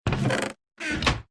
Minecraft Chest Open and Close
minecraft-chest-open-and-close.mp3